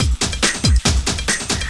04 LOOP09 -R.wav